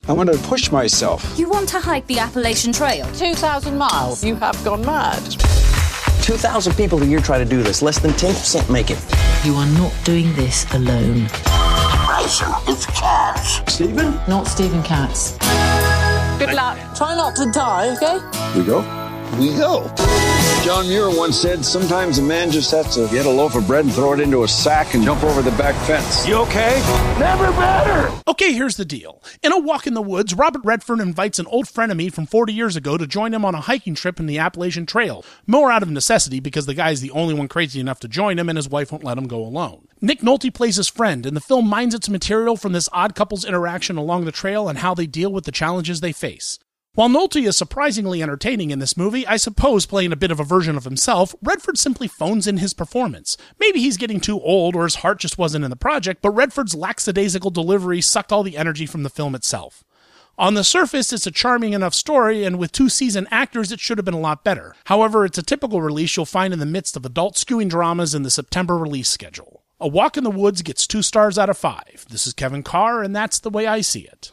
‘A Walk in the Woods’ Movie Review